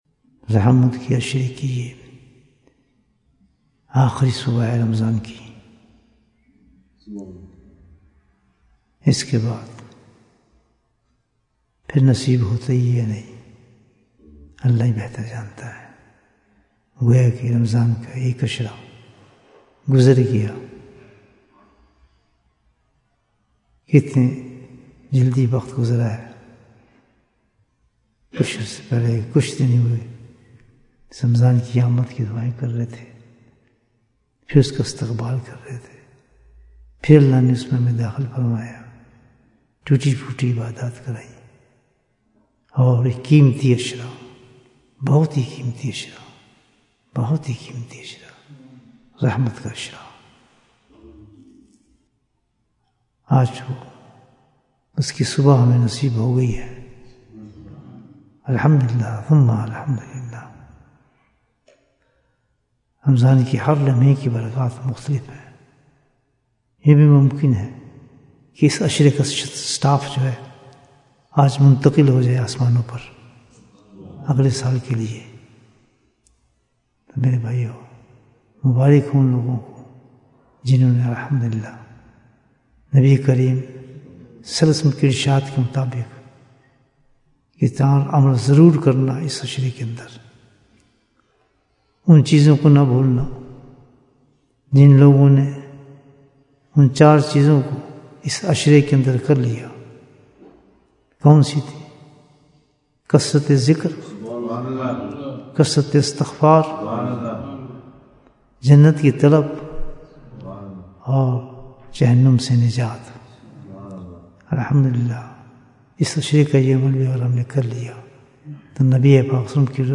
Bayan, 8 minutes
We are fortunate to have attended this gathering.